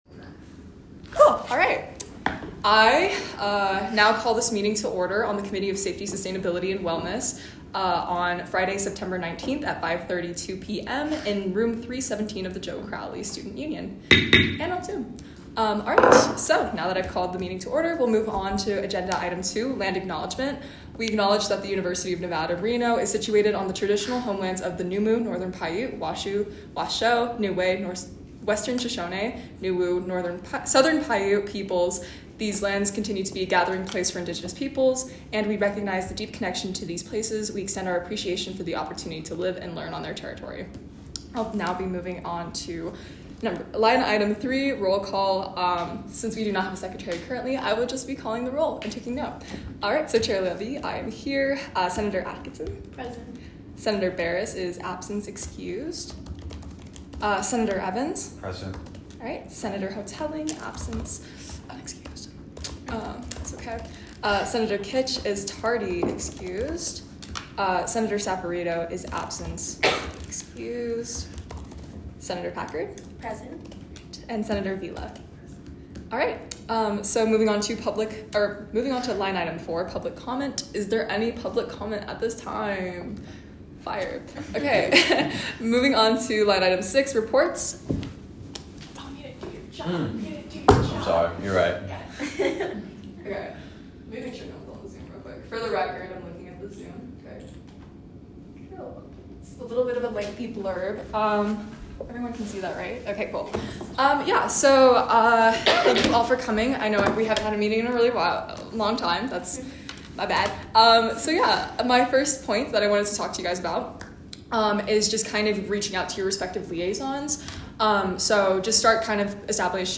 Location : Joe Crowley Student Union Room 317